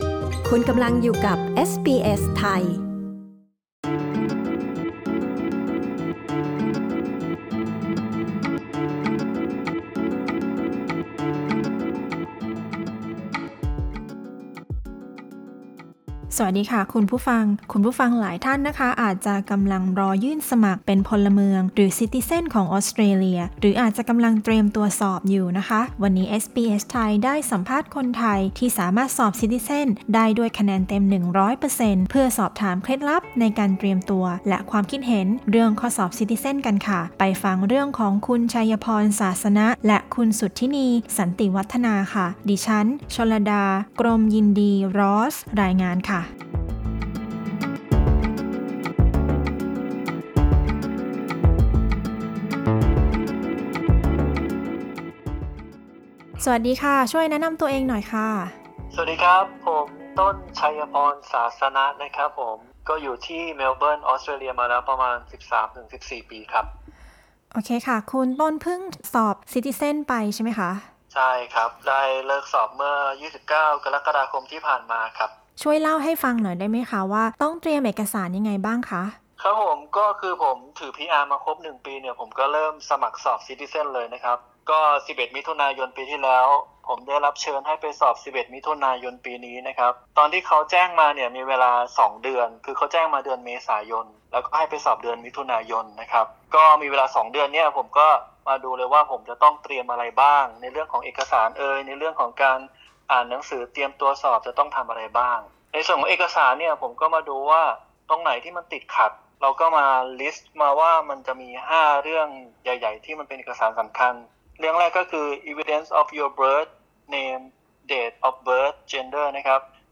interview-citizen_test-final_2.mp3